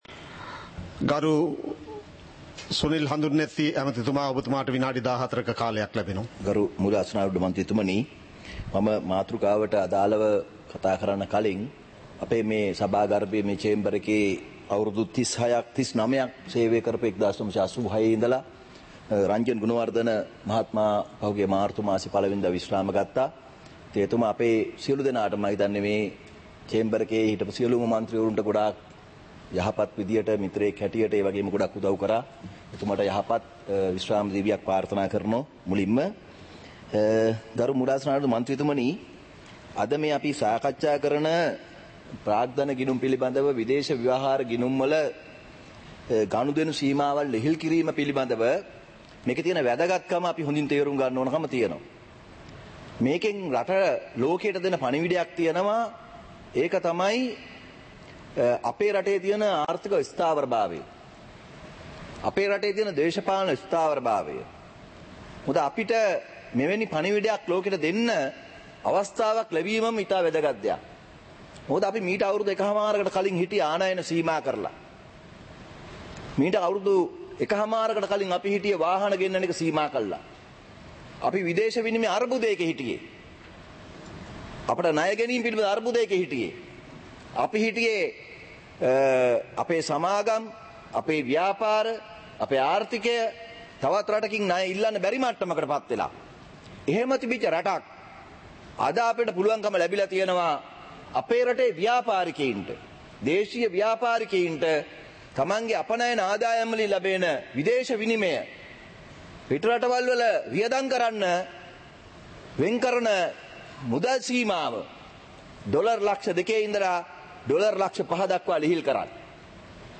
சபை நடவடிக்கைமுறை (2026-03-03)